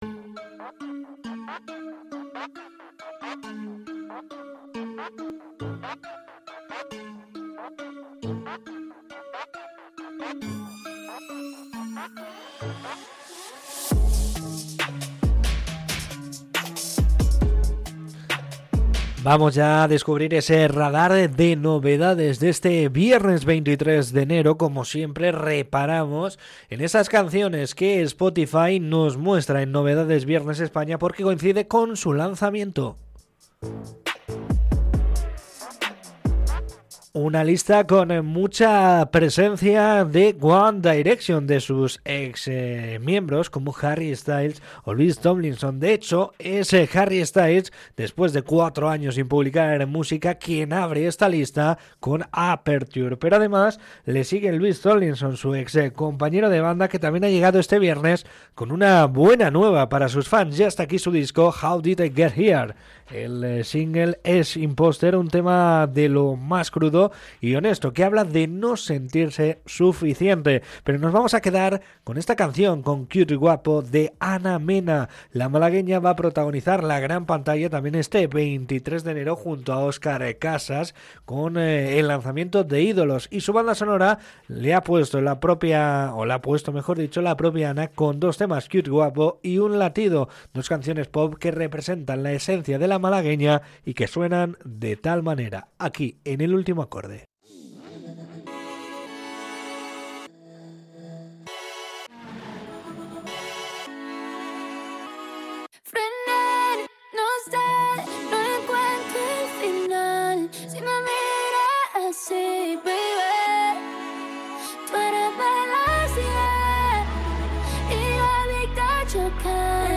Este vieDesde los estudios de Herri Irratia, el repaso a las novedades discográficas ha estado marcado por una coincidencia histórica para los fans del pop británico y por la fuerza de las voces femeninas nacionales que reclaman su sitio en las listas de éxitos.